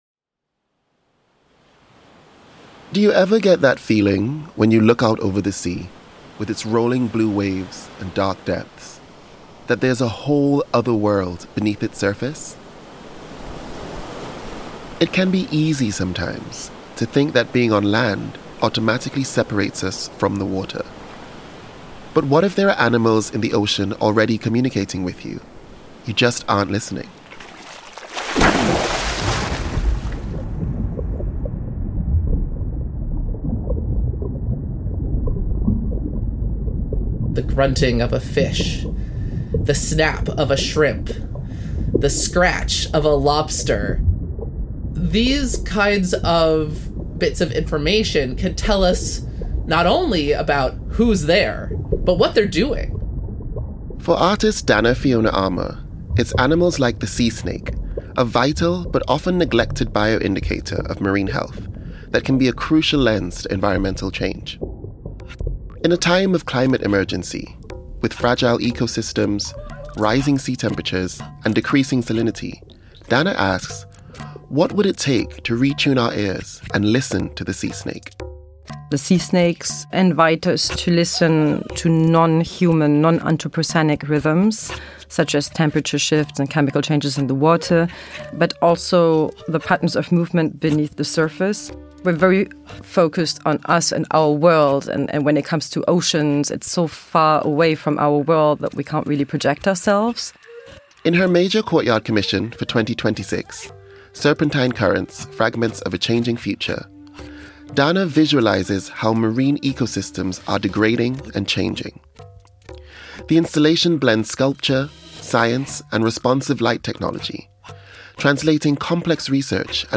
Additional field recordings and sound: